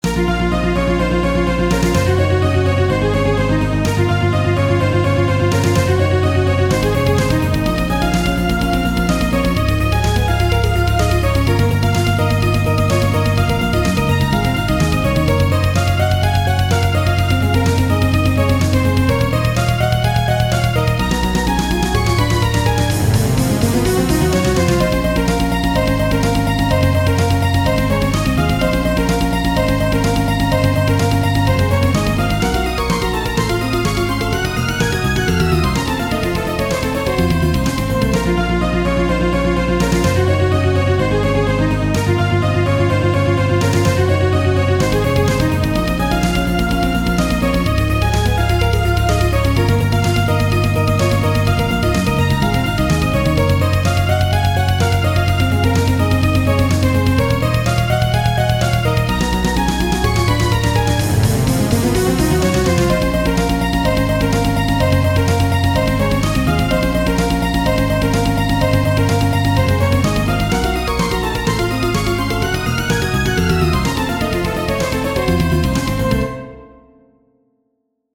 Music / Game Music
game ost remastered soundtrack retro music